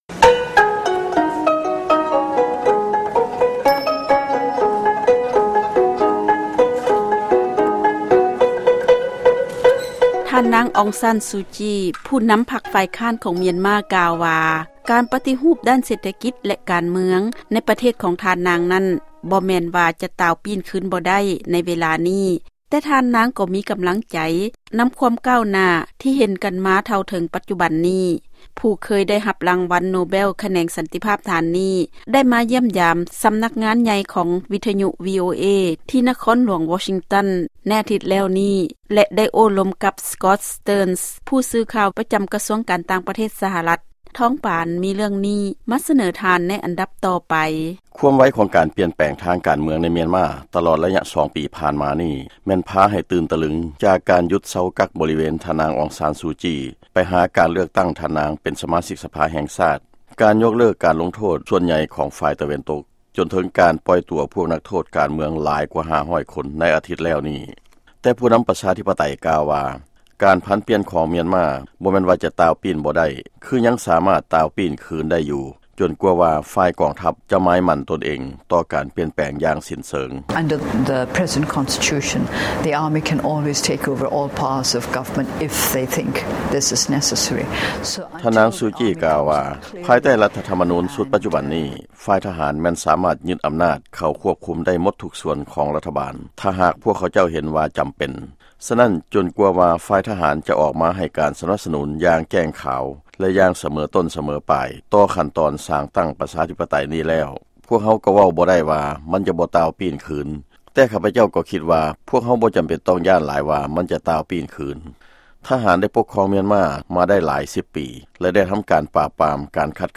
ຟັງຂ່າວທ່ານນາງຊູຈີໃຫ້ສໍາພາດວິທະຍຸວີໂອເອ